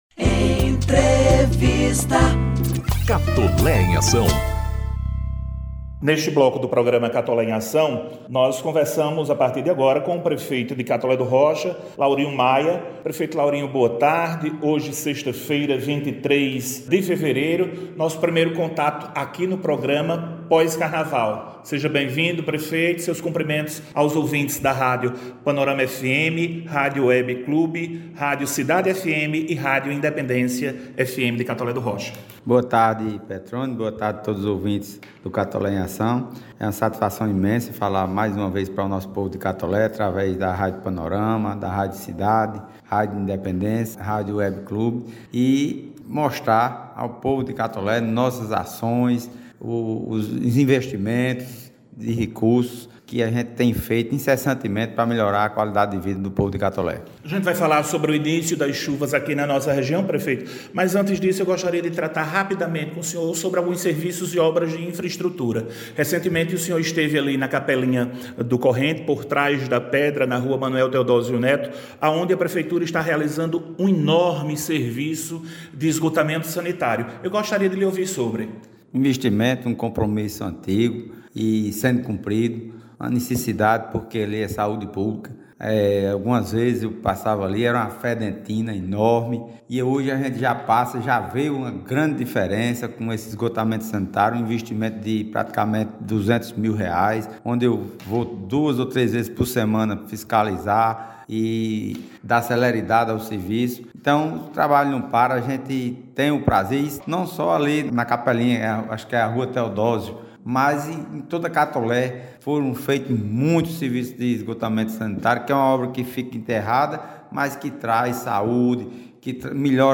O Programa Institucional “Catolé em Ação” – edição n° 125, sexta-feira (23/02) – exibiu entrevista com o prefeito municipal Laurinho Maia.